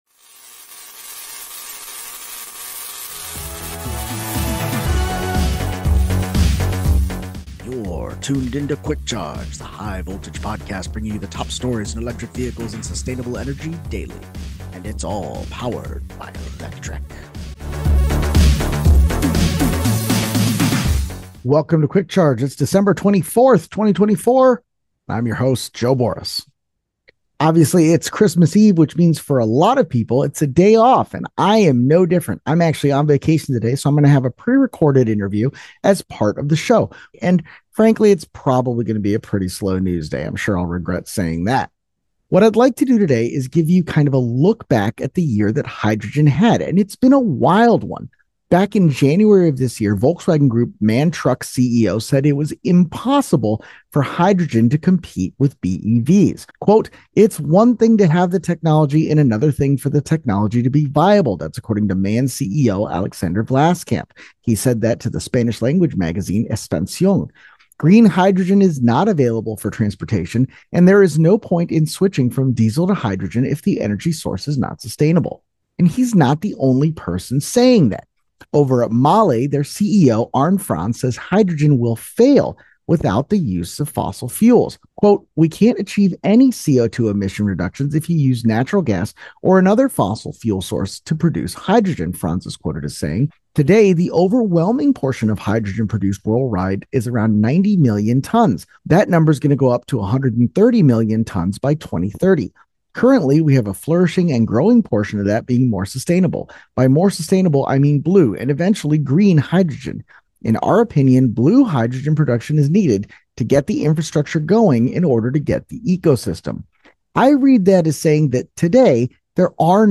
In the course of the conversation we talk about several hydrogen articles posted in 2024, as well as some Honda projects related to CES.